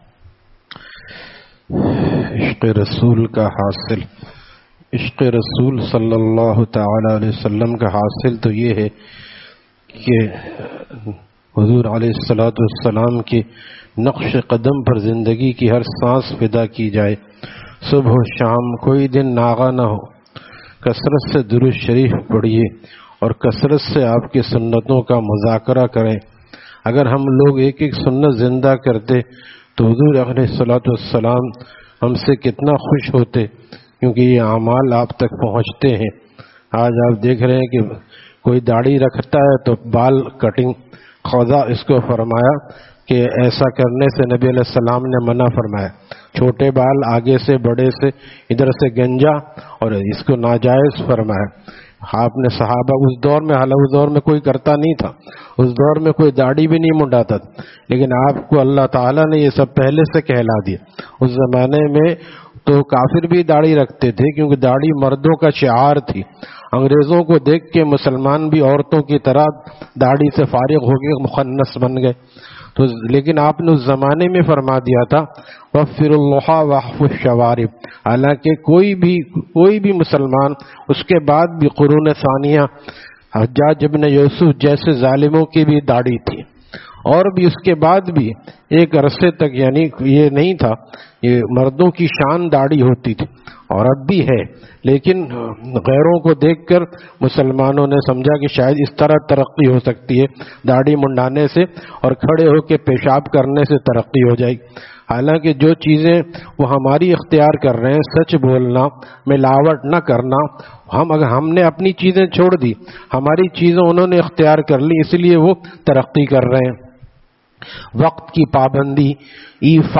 Taleem After Fajar at Jama Masjid Gulzar e Muhammadi, Khanqah Gulzar e Akhter, Sec 4D, Surjani Town